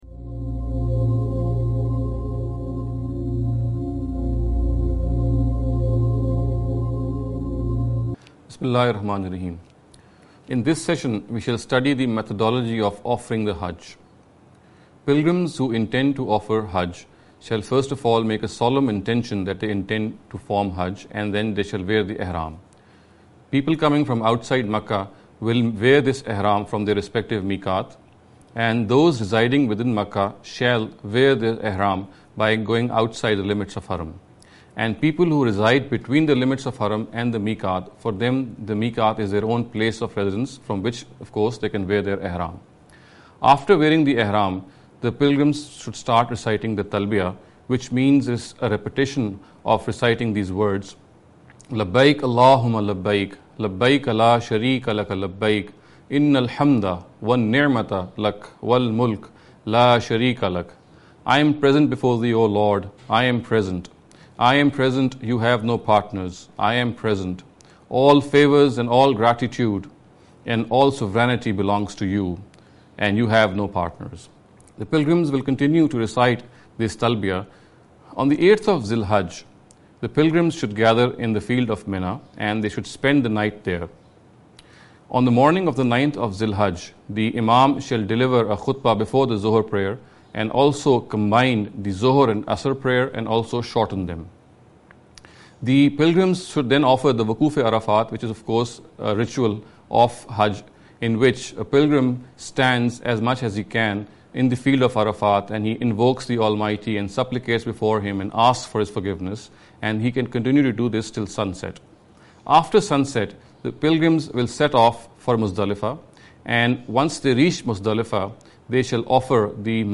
This lecture series will deal with some misconception regarding the Understanding The Qur’an.